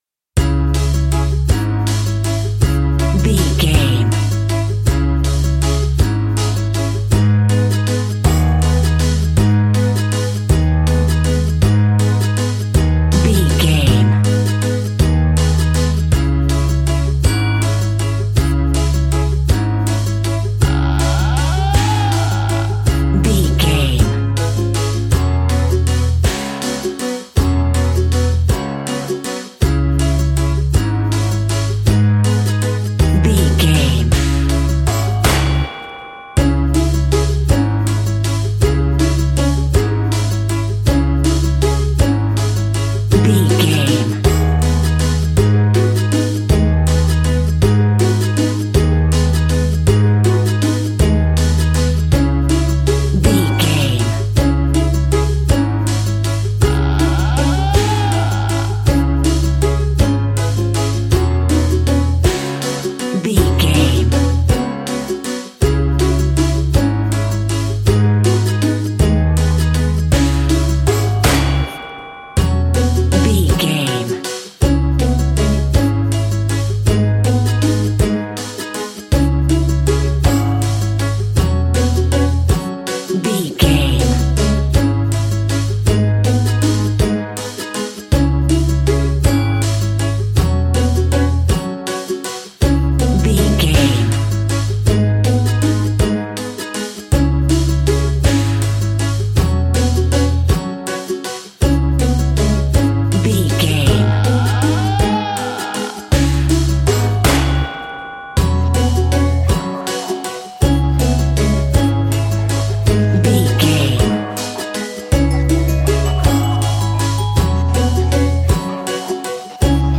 Aeolian/Minor
C#
ominous
haunting
eerie
acoustic guitar
percussion
strings
instrumentals